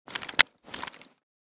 bm16_unjam.ogg